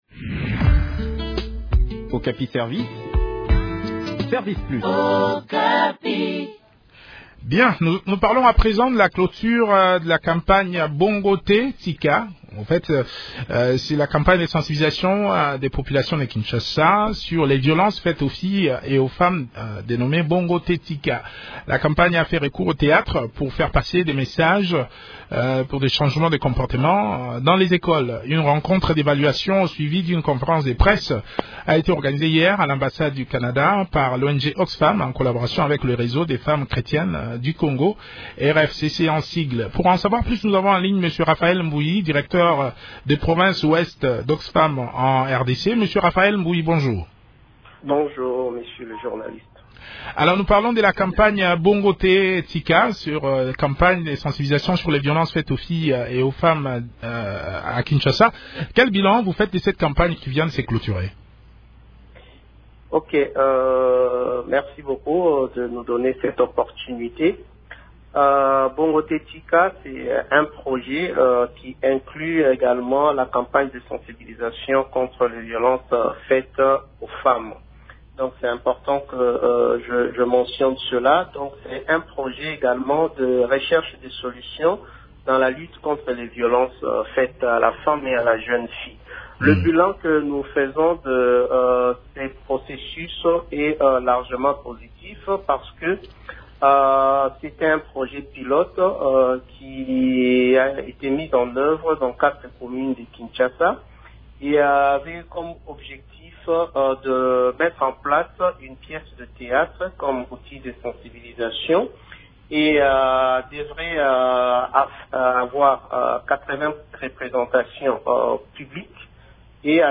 Le point sur le déroulement de cette campagne de sensibilisation dans cet entretien